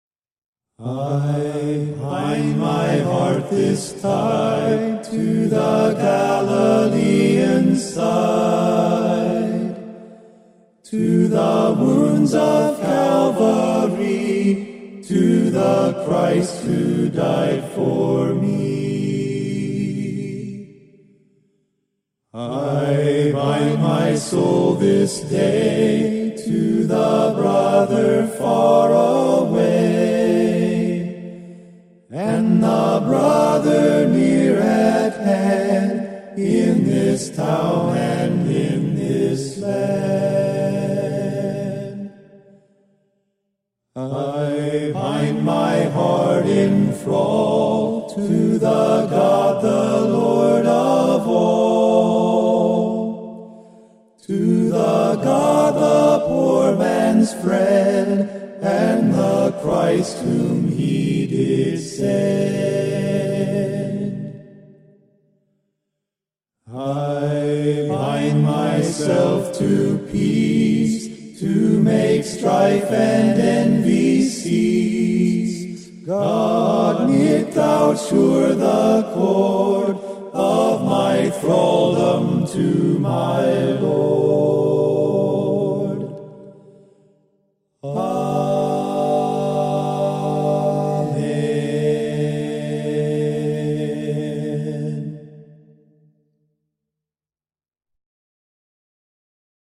HYMN: Lauchlan McLean Ward